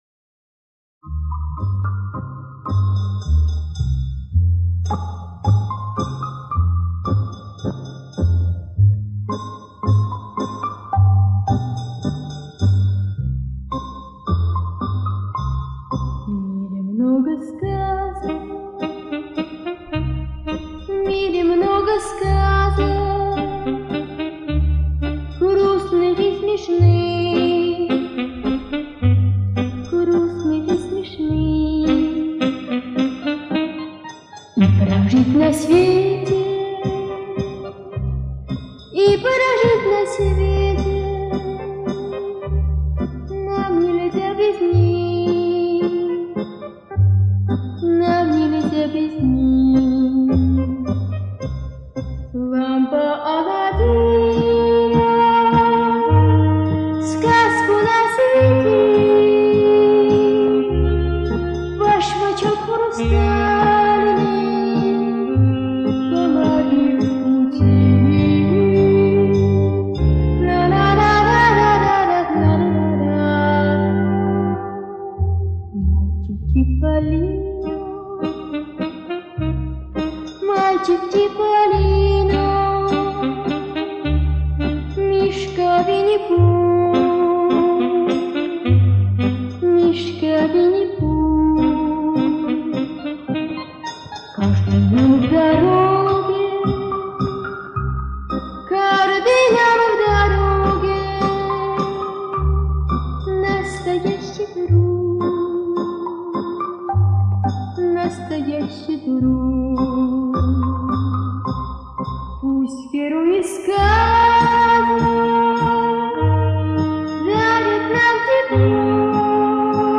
Еще несколько реставраций студийных и живых записей